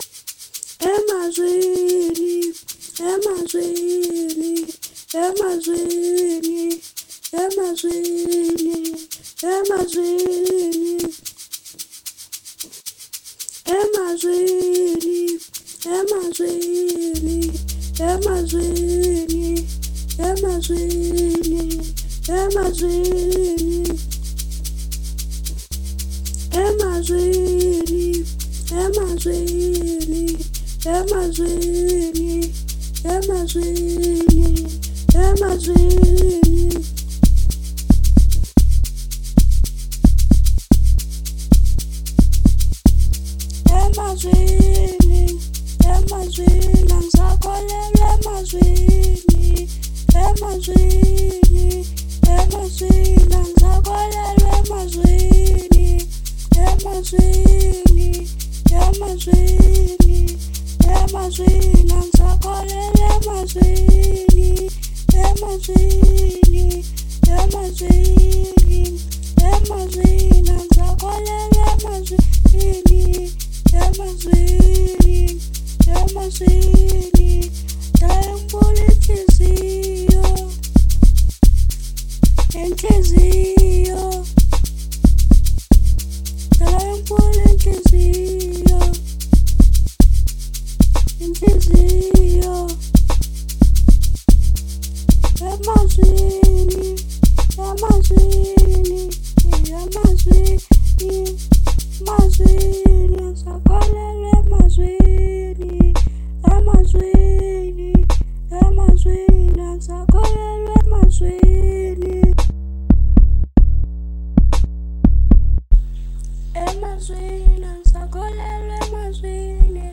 03:25 Genre : Amapiano Size